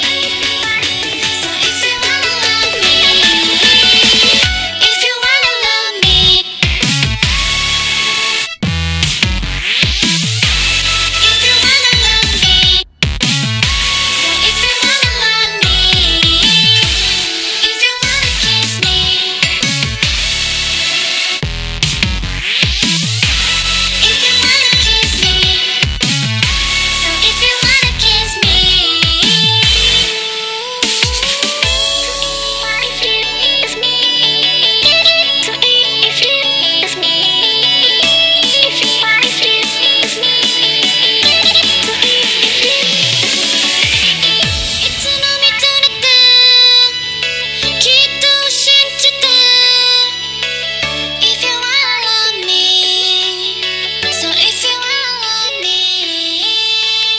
0分50秒あたりから1分50秒くらいまでの約1分間を録音して比較しました。
録音機材：OLYMPUS LINEAR PCM RECORDER LS-20M
JBL Synchros E10NP 有線 イヤホン：WAVはこちら>>>
レコーダーのマイクは比較的高音域の感度が高いので、一般的にはキンキンした音になりがちです。